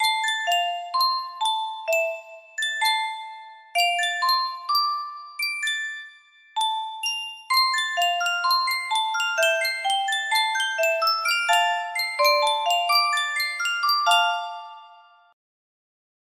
Yunsheng Music Box - Keston 2557 music box melody
Full range 60